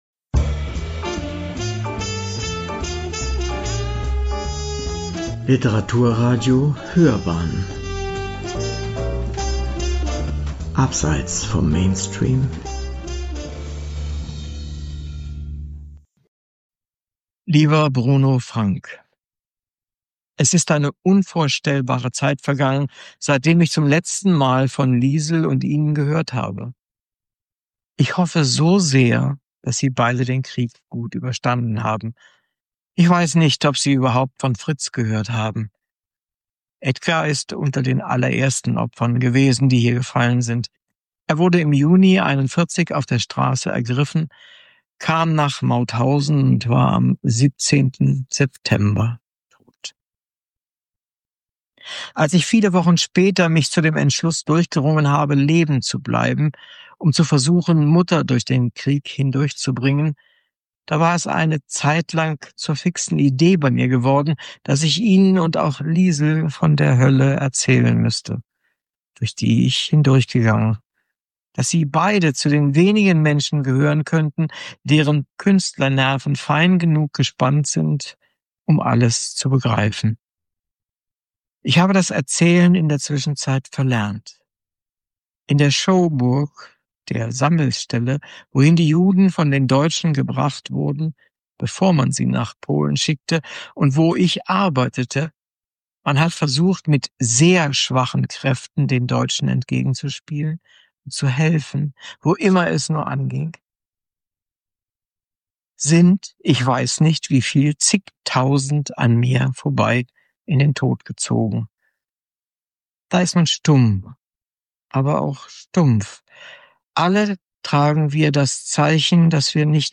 Reportage